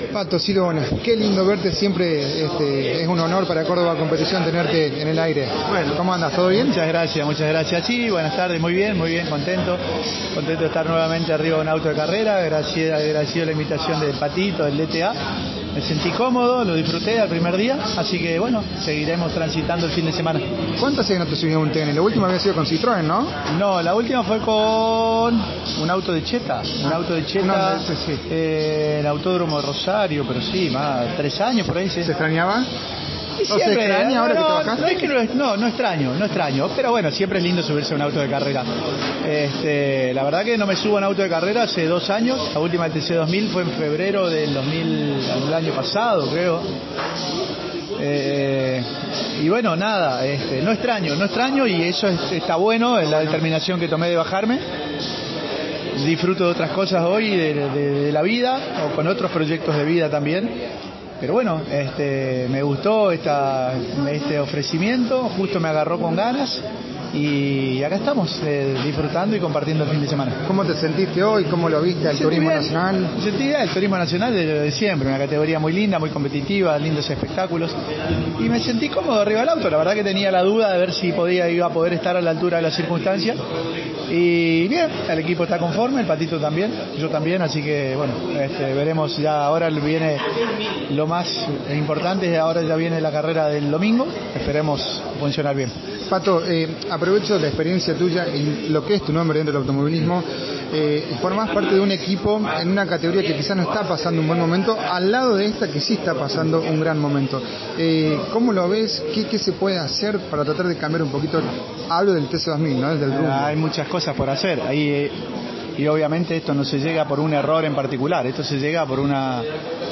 Juan Manuel Silva dialogó con CÓRDOBA COMPETICIÓN, en su retorno a la actividad de la Clase 3 del Turismo Nacional, como invitado de Fabián Yannantuoni.